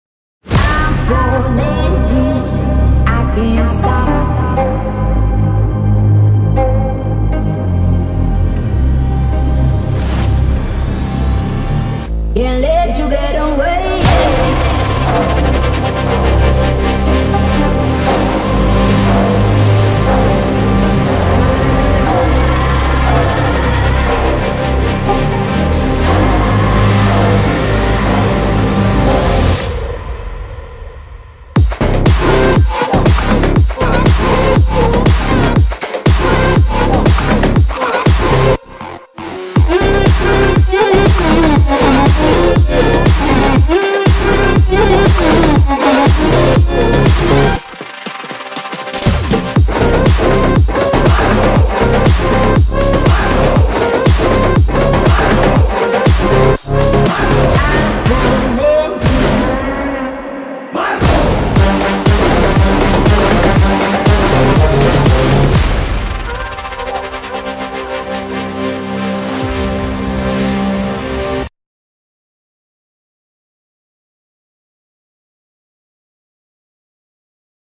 ✔ Soothing, cascading piano motifs
✔ Warm strings and lush ambient textures
bgm , tune , lofi